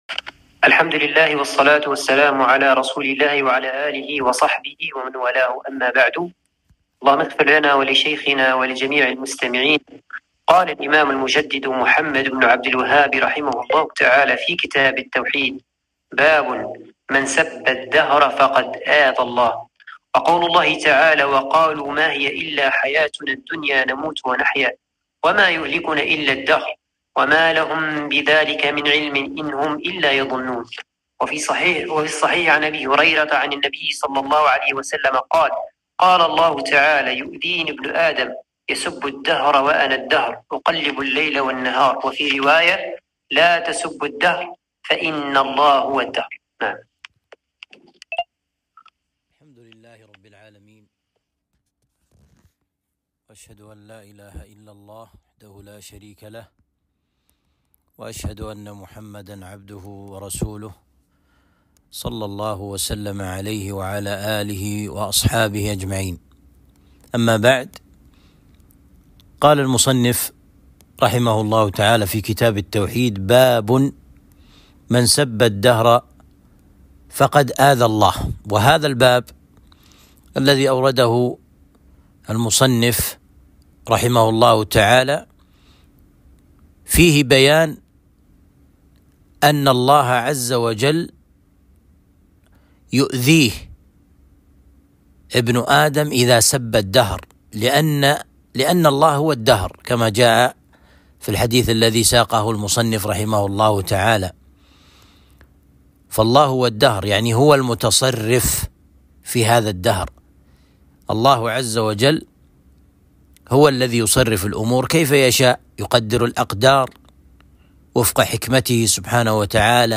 درس شرح كتاب التوحيد